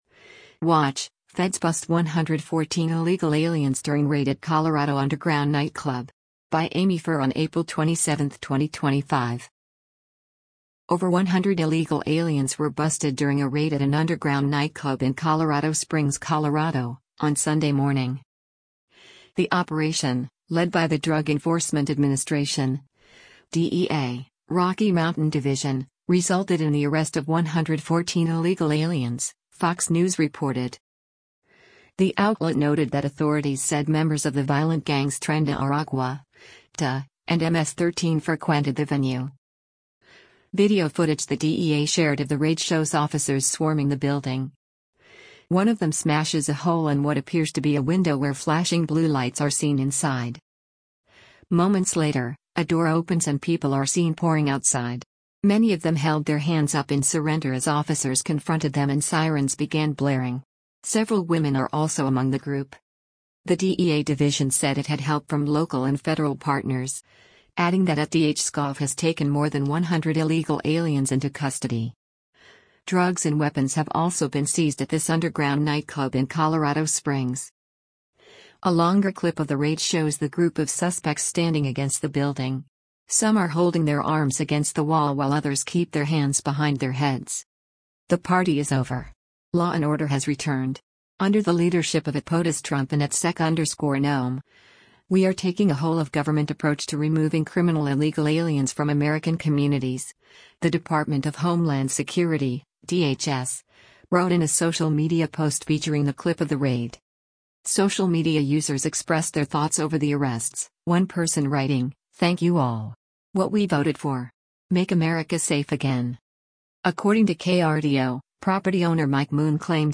Video footage the DEA shared of the raid shows officers swarming the building.
Many of them held their hands up in surrender as officers confronted them and sirens began blaring.